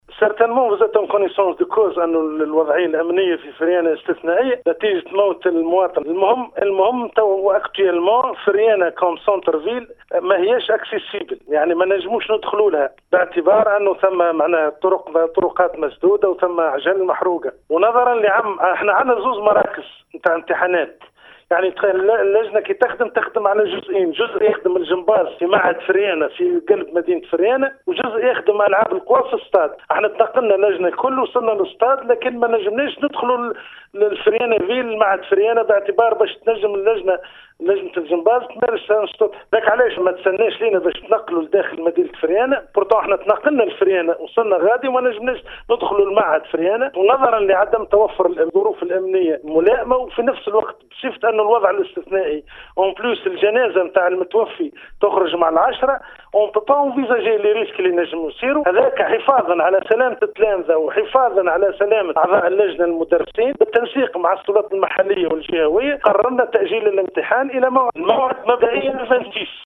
في إتصال بإذاعة السيليوم أف أم